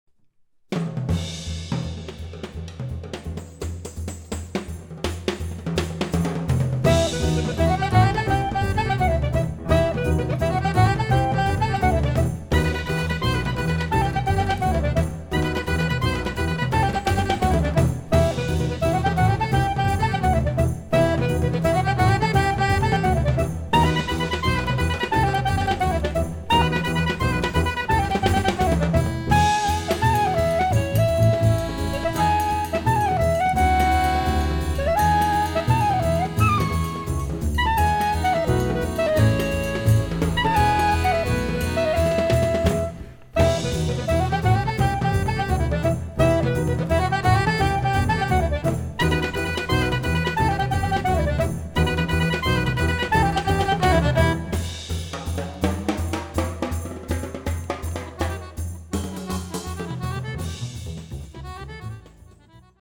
tenor saxophone, clarinet
piano, accordion
drums